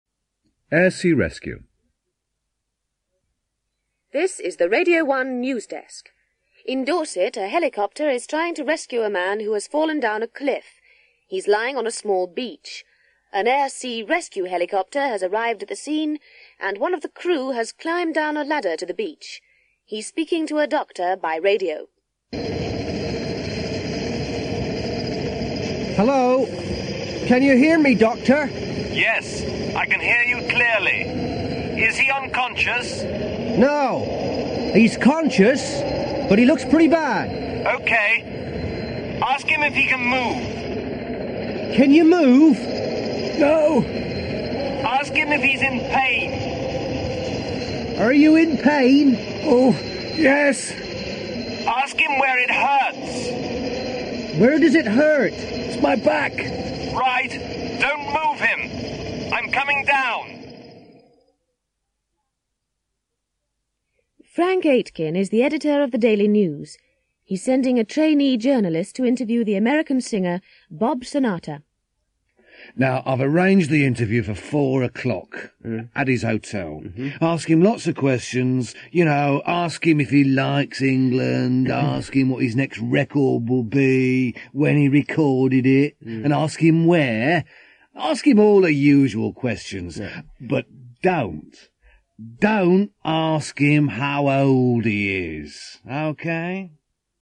English conversation